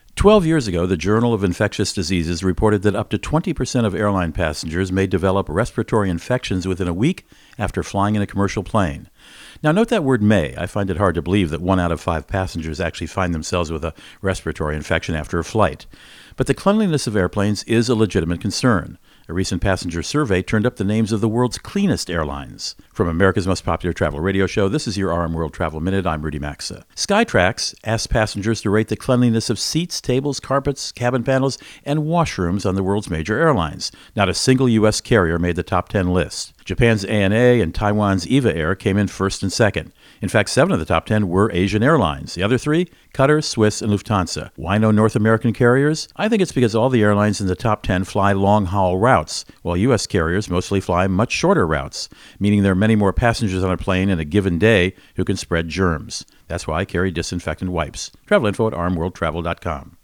America's #1 Travel Radio Show
Co-Host Rudy Maxa | Why are American Airplanes Dirty?